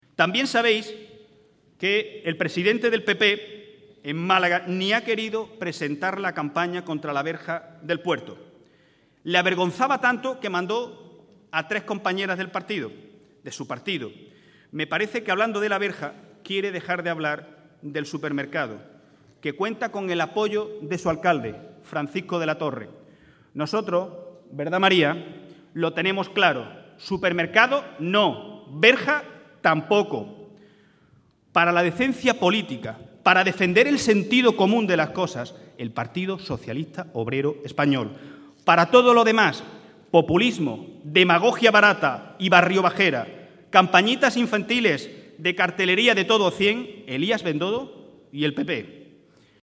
Recuerda en el comité provincial celebrado en Vélez-Málaga que el PP en la Diputación miraba el carné para dar subvenciones